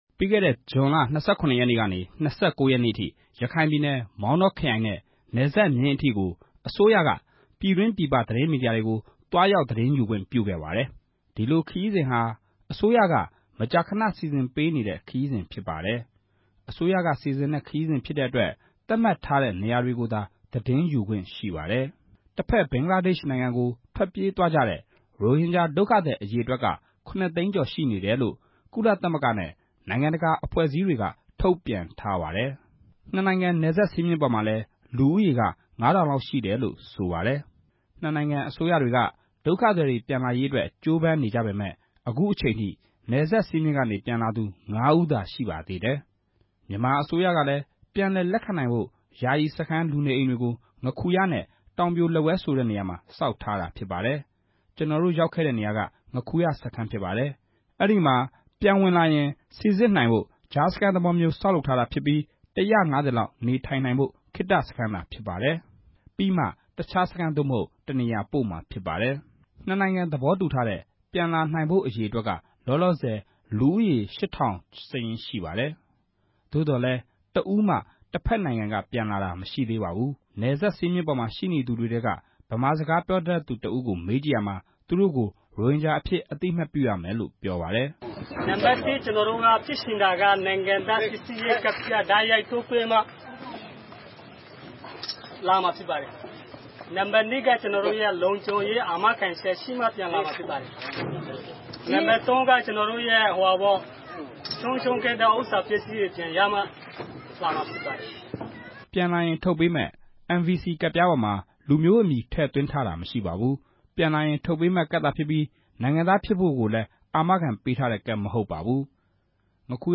မောင်တောအခြေအနေ သတင်းပေးပို့ချက်